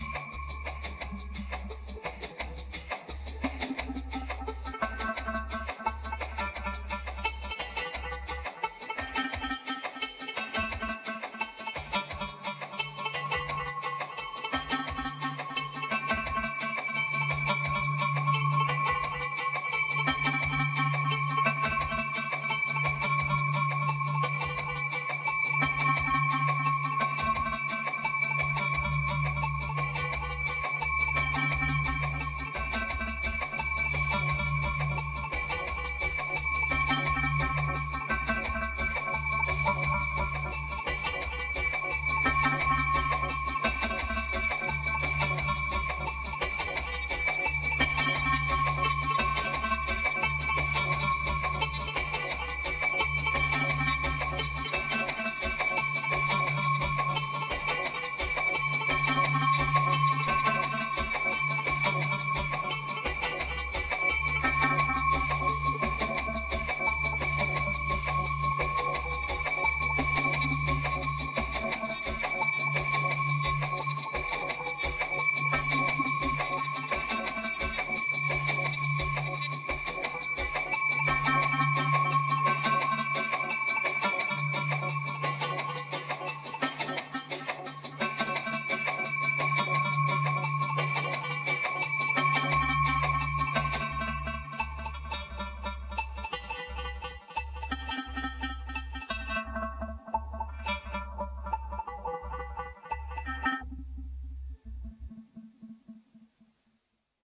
2278📈 - 68%🤔 - 86BPM🔊 - 2017-04-01📅 - 249🌟